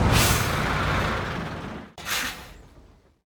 train-engine-stop-2.ogg